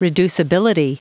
Prononciation du mot reducibility en anglais (fichier audio)
Prononciation du mot : reducibility